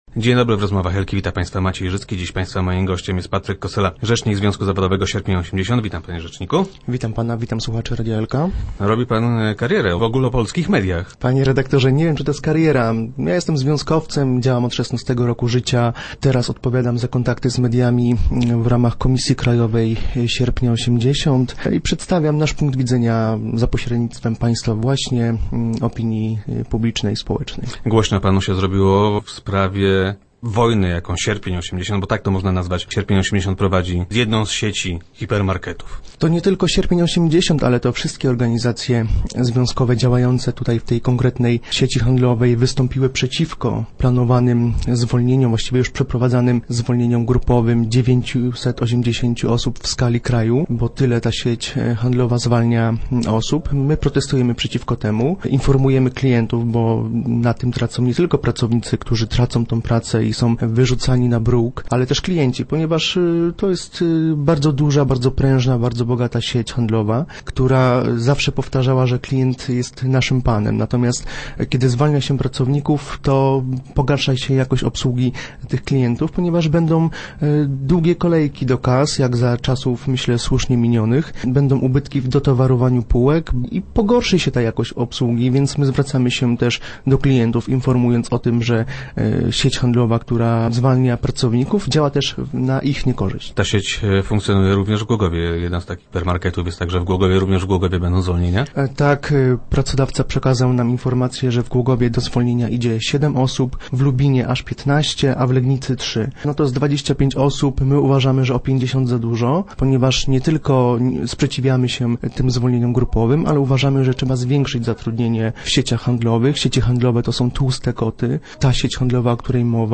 10.06.2012. Radio Elka